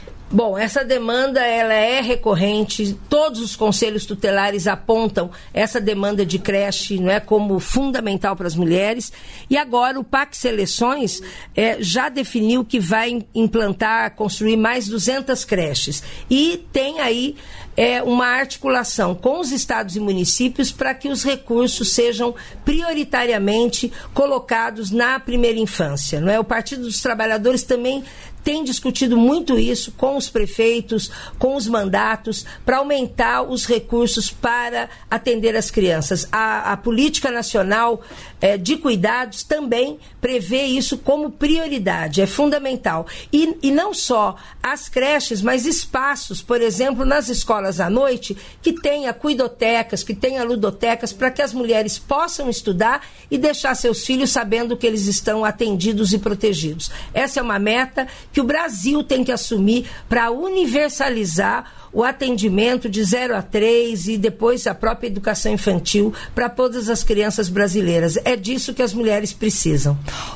Trecho da participação do ministro do Empreendedorismo, da Microempresa e da Empresa de Pequeno Porte, Márcio França, no programa "Bom Dia, Ministro" desta quarta-feira (30), nos estúdios da EBC em Brasília (DF).